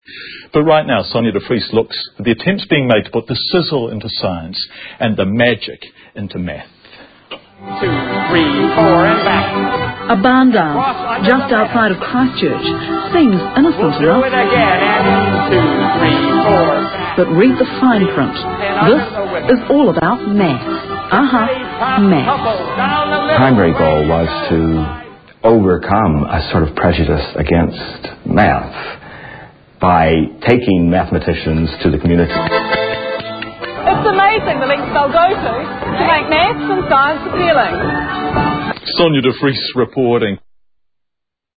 Sound bites from broadcast, more TV screen shots.